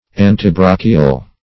Search Result for " antibrachial" : The Collaborative International Dictionary of English v.0.48: Antibrachial \An`ti*brach"i*al\, a. (Anat.) Of or pertaining to the antibrachium, or forearm.